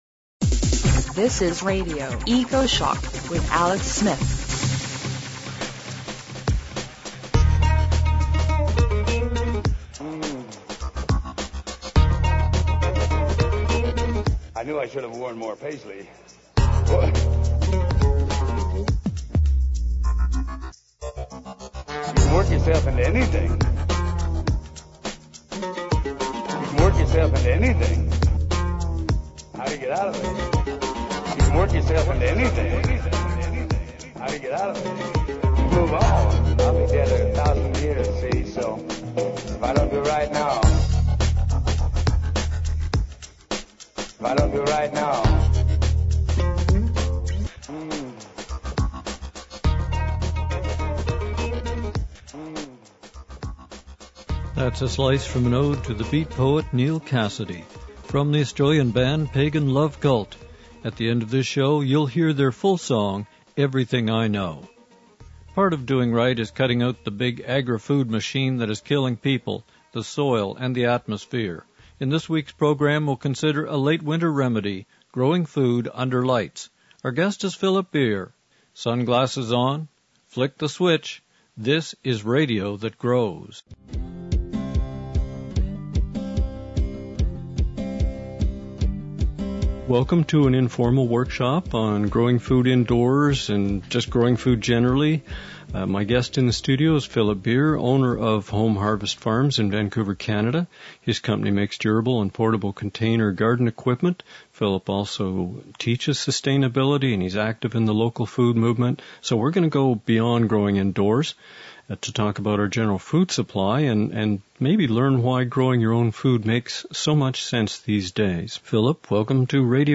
We start with a show and tell experiment right here in the Radio Ecoshock studio.